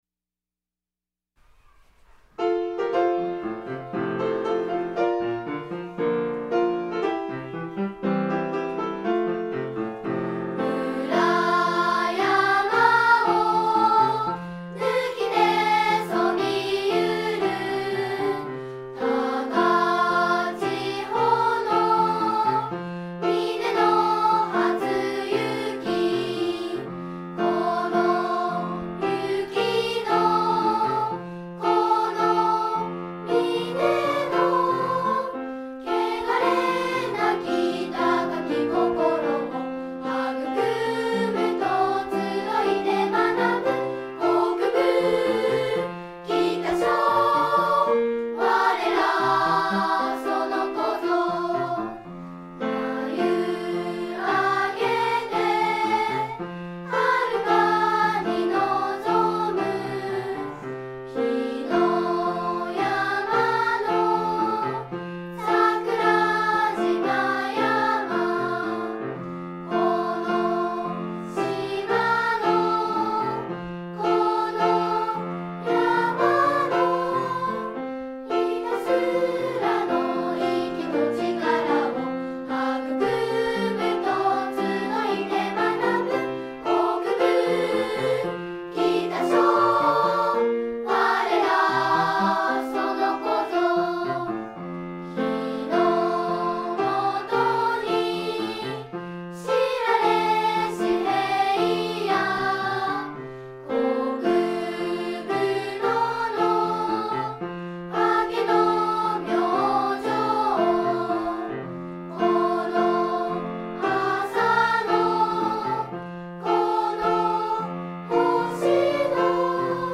■ 校歌